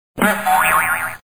SFX跳摔倒音效下载